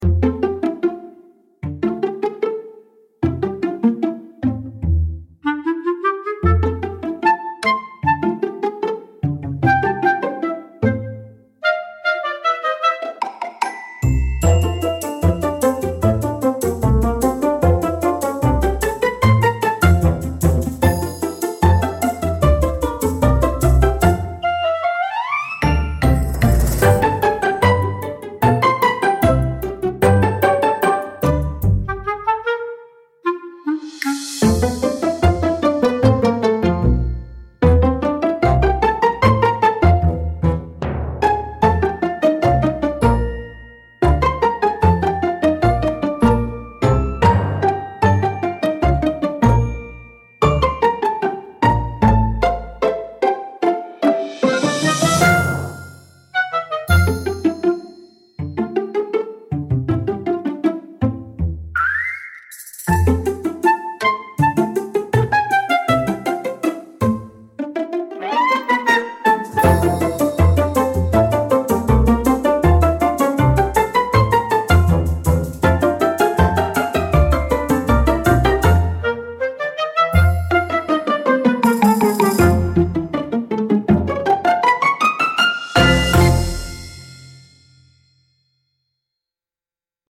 quirky upbeat music with comedic timing, pizzicato strings and playful woodwinds